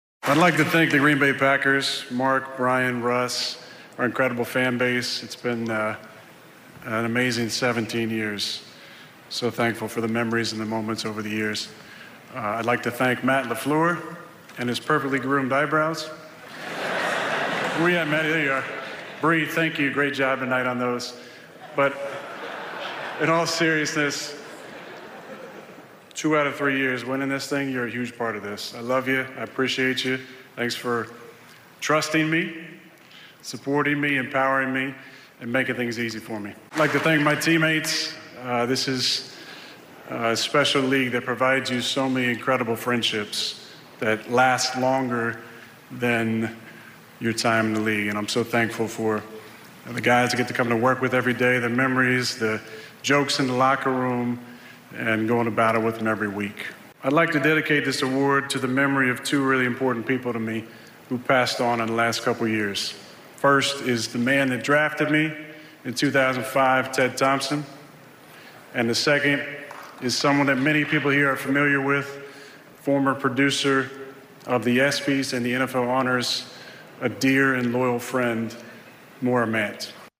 Rodgers now has the second most MVP awards in NFL history behind only Manning (5) who announced Aaron as the winner at the NFL Honors Ceremony in Los Angeles.
Donning a tan suit, Rodgers delivered an acceptance speech with grace and humor.
rodgers-mvp-speech.mp3